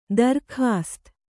♪ darkhāst